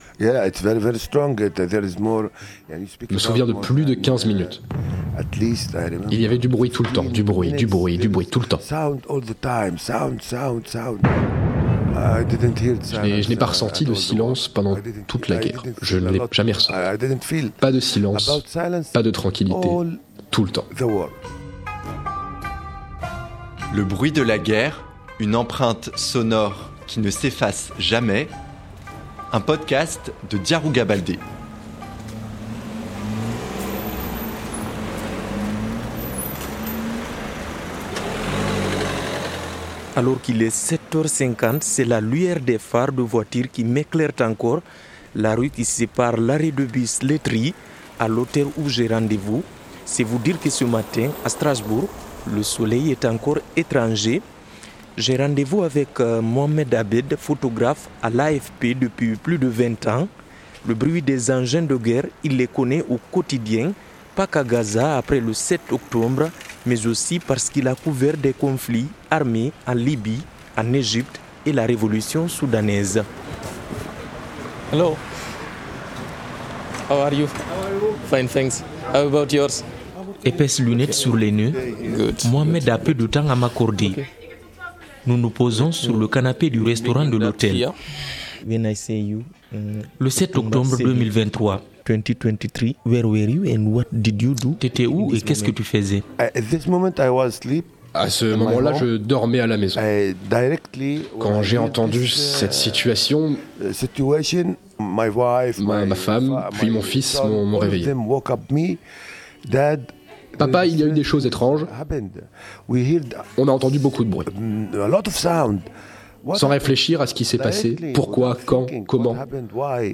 Mon podcast explore le rapport intime qu’entretiennent les personnes venues de pays en guerre avec le bruit et le silence. Deux témoins racontent